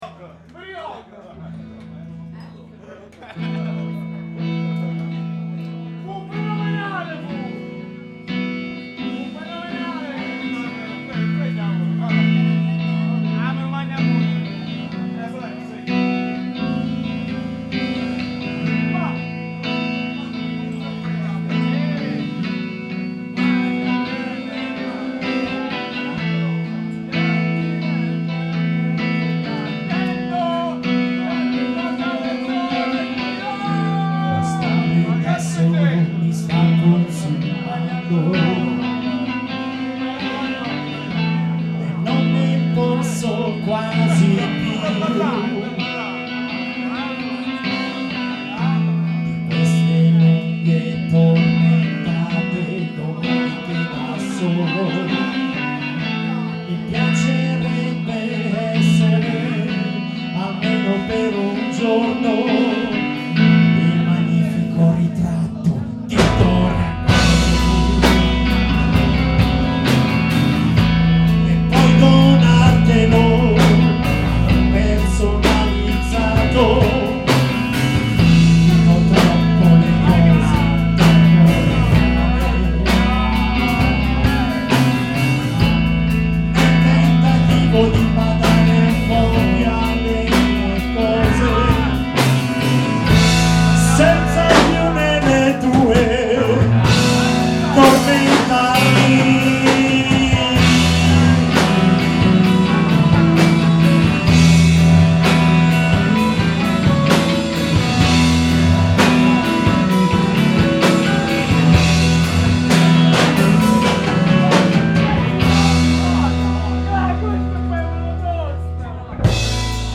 with ORTF stereo configuration,